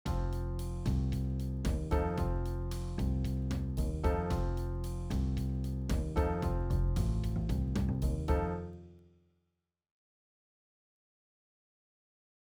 ファンク
ファンクは1拍目と4拍目裏ににコードがあるのがおすすめ
4拍目に高揚感もって１拍目に向かうイメージ
ファンク.wav